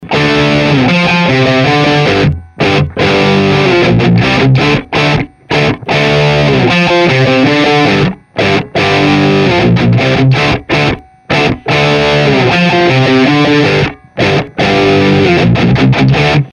Может удастся показать характер перегруза.